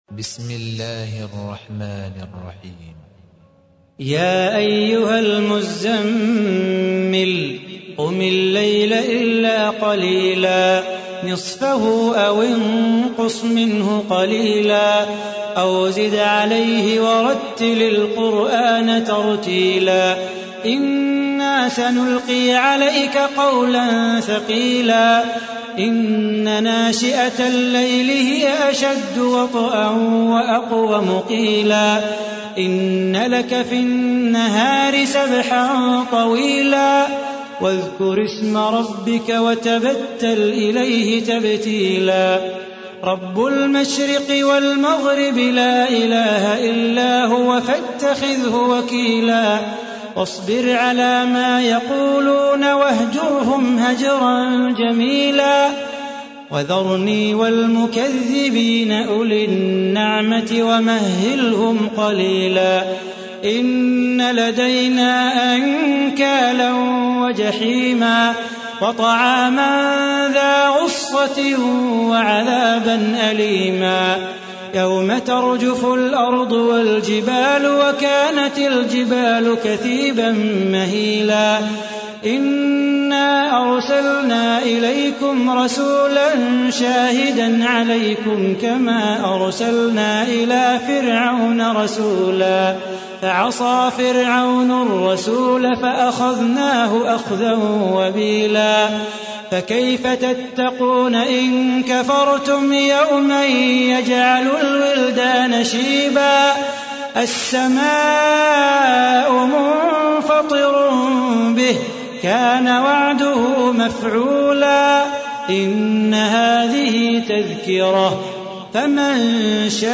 تحميل : 73. سورة المزمل / القارئ صلاح بو خاطر / القرآن الكريم / موقع يا حسين